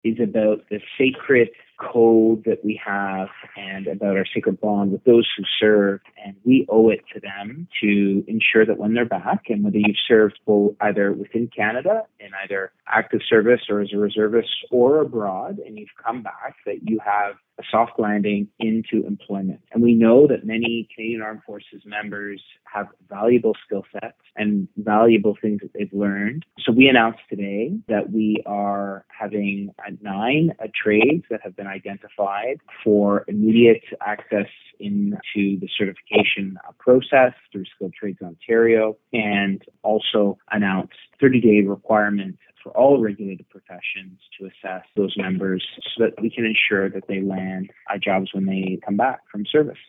Minister of Labour, Immigration, Training and Skills Development for Ontario, David Piccini, says it’s about helping those who give their life to protecting our country.